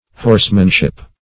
Horsemanship \Horse"man*ship\, n.